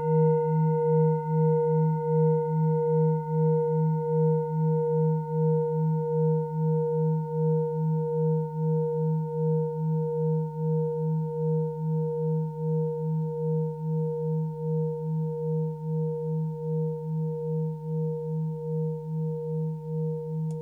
Tibet Klangschale Nr.16
Sie ist neu und wurde gezielt nach altem 7-Metalle-Rezept in Handarbeit gezogen und gehämmert.
Hörprobe der Klangschale
(Ermittelt mit dem Filzklöppel oder Gummikernschlegel)
Diese Frequenz kann bei 160Hz hörbar gemacht werden; das ist in unserer Tonleiter nahe beim "E".
klangschale-tibet-16.wav